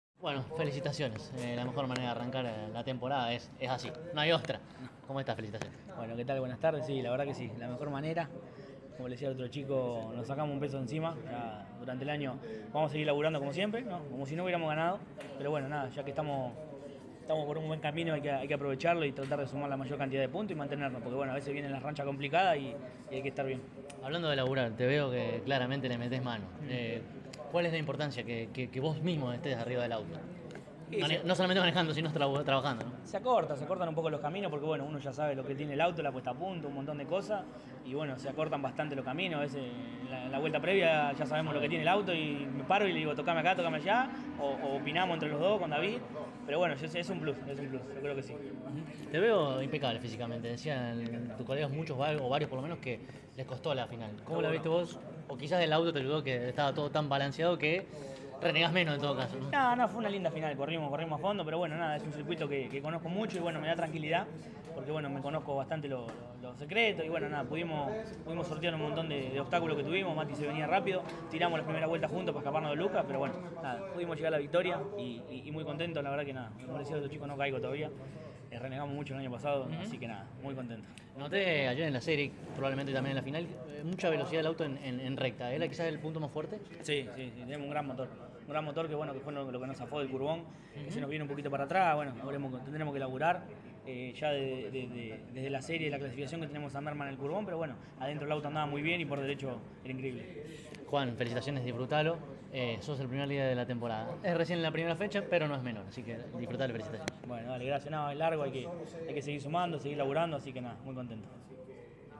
CÓRDOBA COMPETICIÓN estuvo allí presente y dialogó con los protagonistas más importantes al cabo de cada una de las finales.